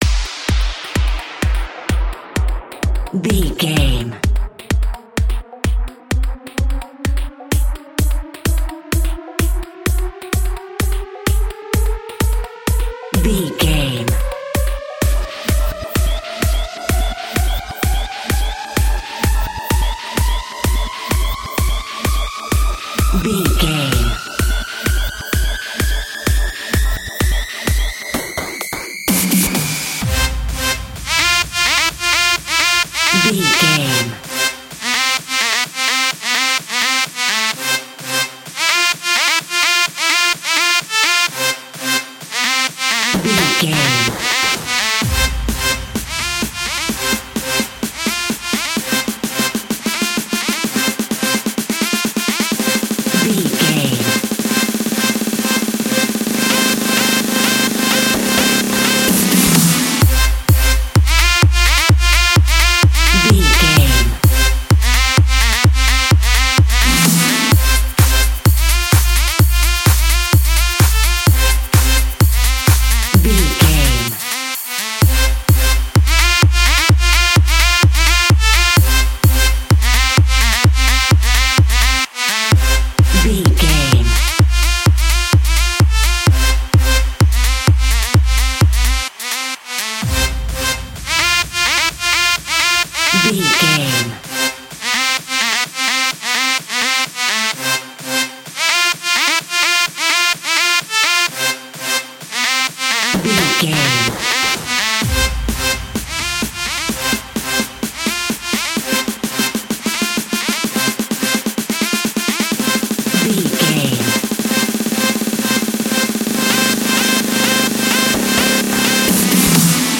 Epic / Action
Fast paced
Ionian/Major
groovy
uplifting
futuristic
driving
energetic
repetitive
bouncy
synthesiser
drums
drum machine
trance
acid house
electronic
uptempo
synth leads
synth bass